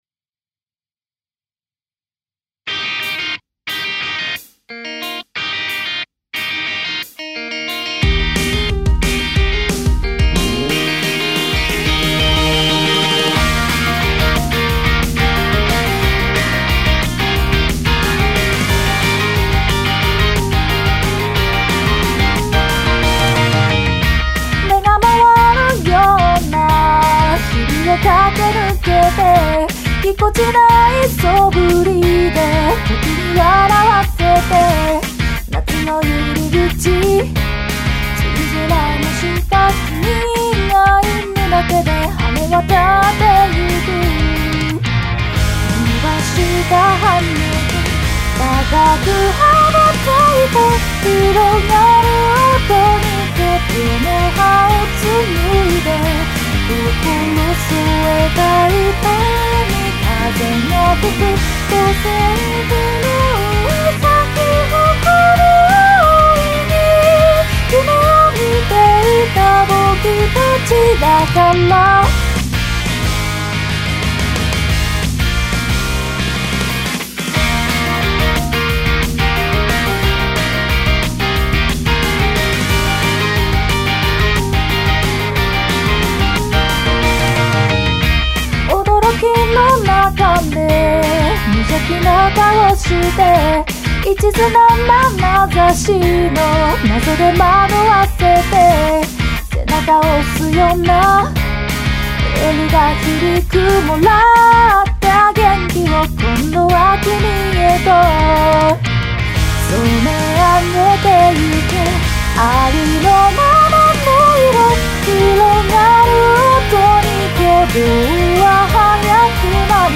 伴奏＋歌